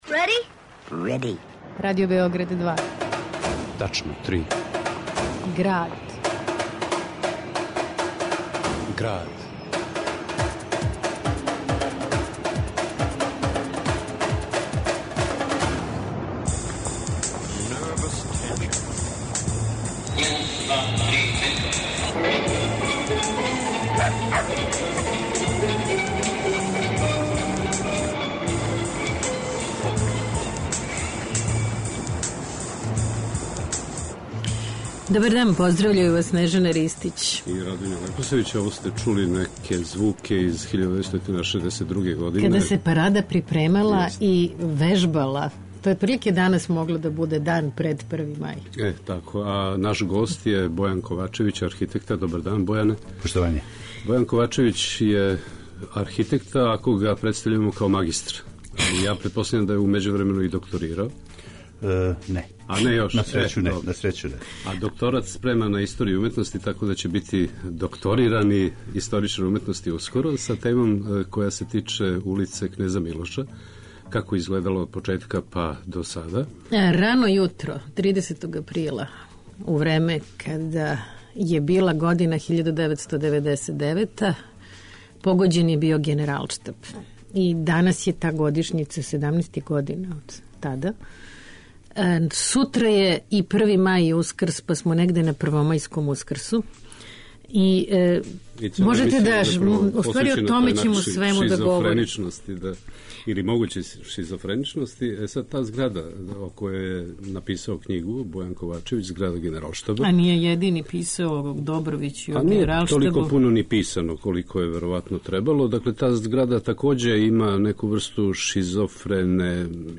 А у фичеру о паради и истоименом филмском документарцу из 1962, чују се и Душан Макавејев, Марко Бабац, Милан Влајчић...